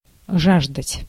Ääntäminen
US
IPA : /kreɪv/